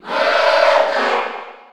Category:Bowser Jr. (SSB4) Category:Crowd cheers (SSB4) You cannot overwrite this file.
Morton_Cheer_Spanish_PAL_SSB4.ogg